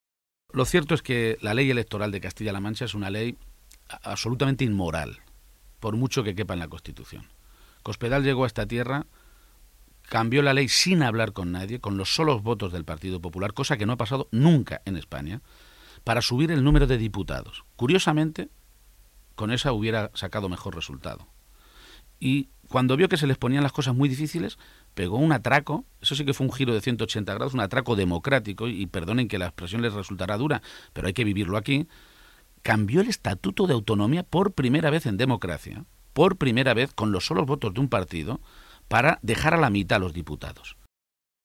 Cortes de audio de la rueda de prensa
Audio Page-entrevista Onda Cero 3